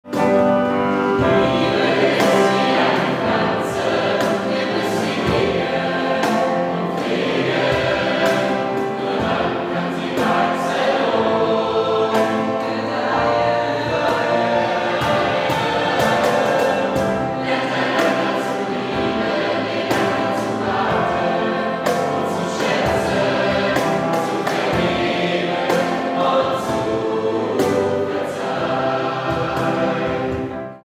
Notation: SATB
Tonart: C, D
Taktart: 4/4
Tempo: 112 bpm
Parts: 2 Verse, 2 Refrains
Noten, Noten (Chorsatz)